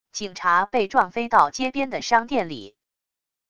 警察被撞飞到街边的商店里wav音频